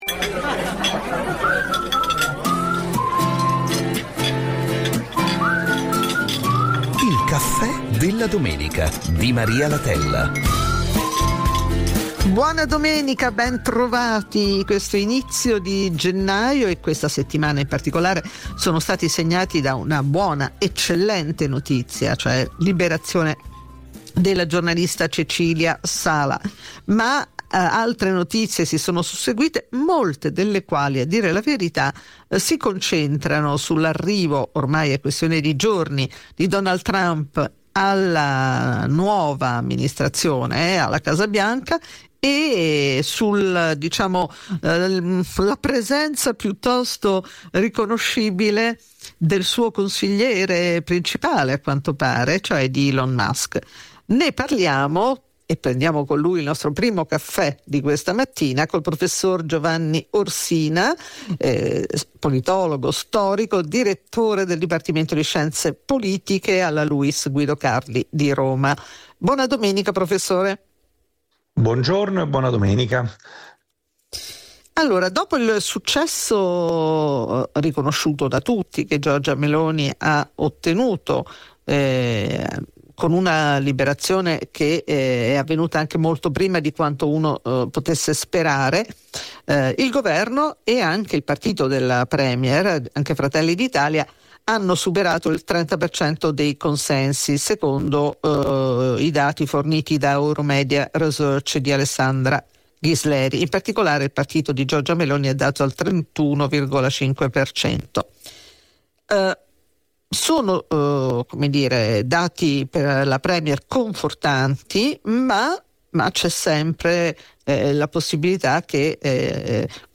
Ogni domenica un caffè con Maria Latella e un ospite, con cui discutere quanto è accaduto in settimana e di quel che ci attende per quella successiva.
Un nuovo spazio nel quale Radio24 offre agli ascoltatori interessati alla politica e all'attualità il metodo delle interviste targato Latella.